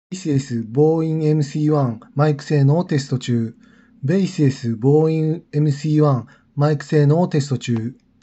マイク性能は平均レベル